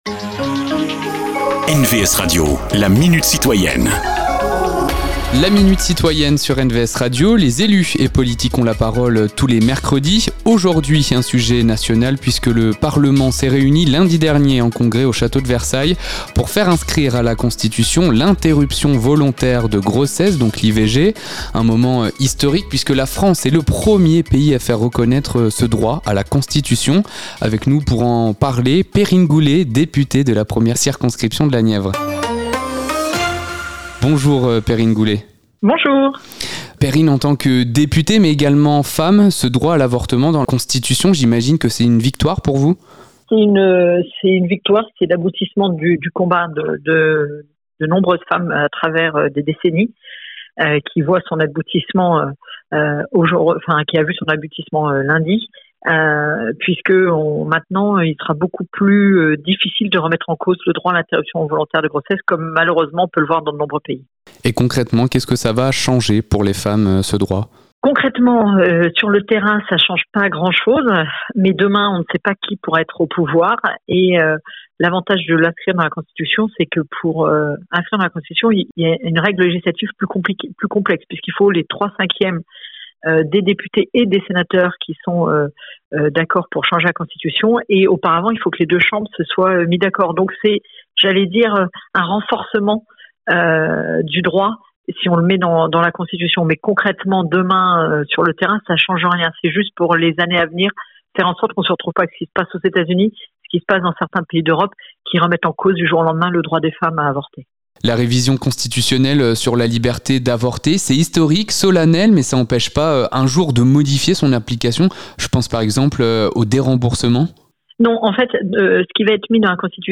Cette semaine Perrine Goulet, deputée de la 1er circonscription de la Nièvre